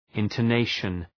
{,ıntəʋ’neıʃən}